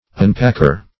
Unpacker \Un*pack"er\, n. One who unpacks.